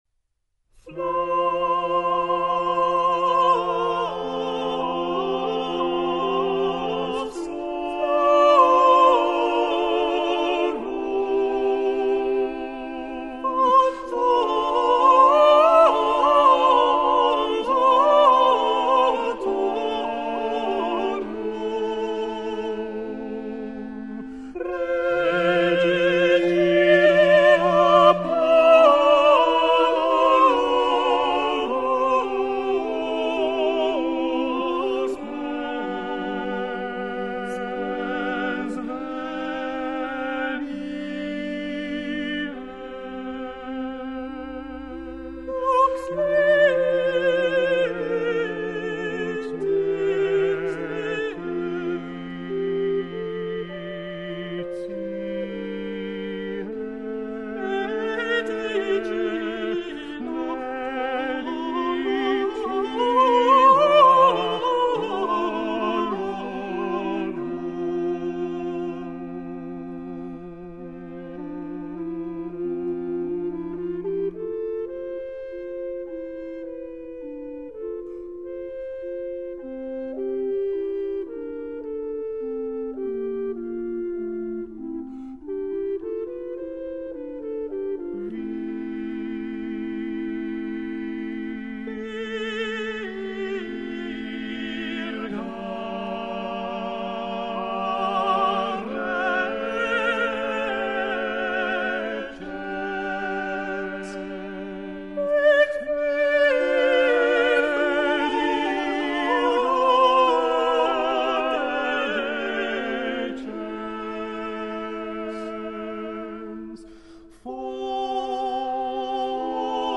音樂類型：古典音樂